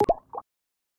Ice cube Bubble Notification.wav